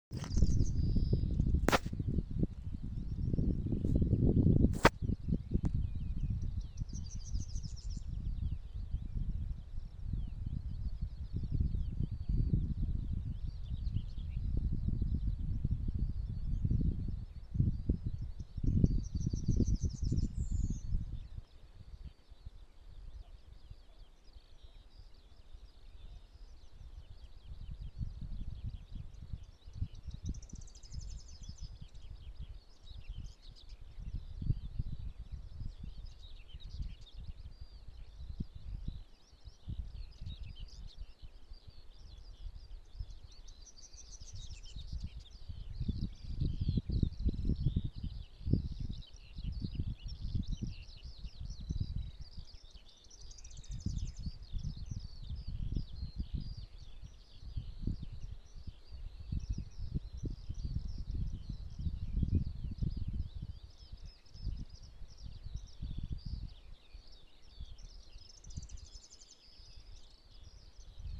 Lielā stērste, Emberiza calandra
Pagajušā gada vietā, atbild neilgi pēc provokācijas sākuma ar nepilnu dziesmu, tad uzdzied dažas pilnas, pārsvarā dzied 'dzeltenās stērstes balsī ar lielās stērstes sākumu' brīžiem iedziedot arī nepārprotamu dzeltenās stērstes dziesmu.
Ierakstā visas stērstu dziesmas izpilda lielā stērste.